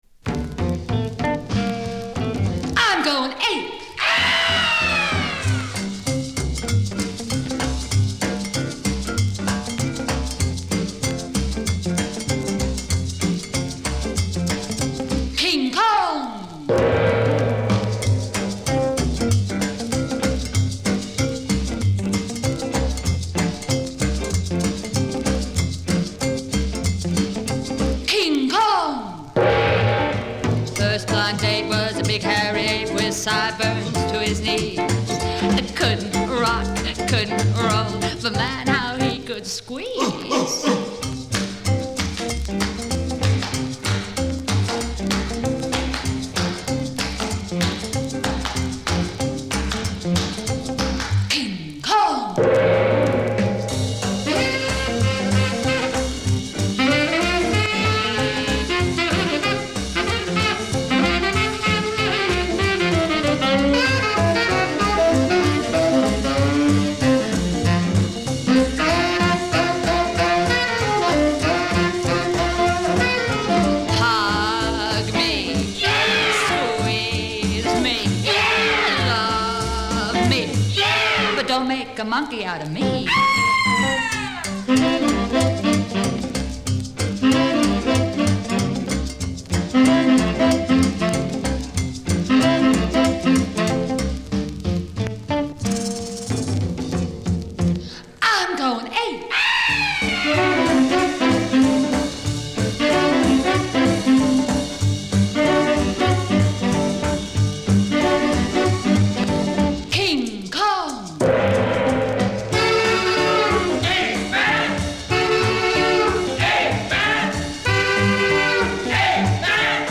novelty songs